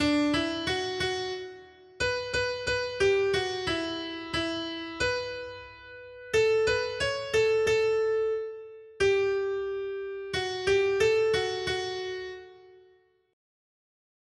Noty Štítky, zpěvníky ol593.pdf responsoriální žalm Žaltář (Olejník) 593 Skrýt akordy R: Odpusť, Pane, co jsem zavinil hříchem. 1.